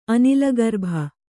♪ anilagarbha